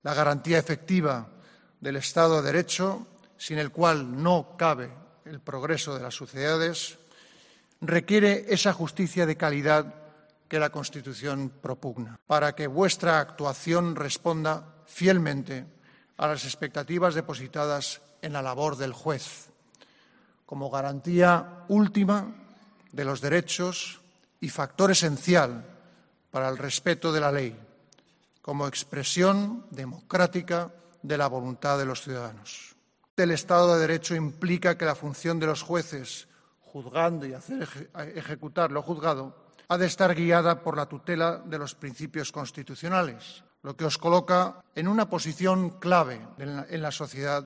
Felipe VI ha manifestado este mensaje de apoyo y confianza en presencia de la cúpula judicial durante la ceremonia de entrega de los despachos a la nueva promoción de jueces que ha tenido lugar en el Auditori de Barcelona.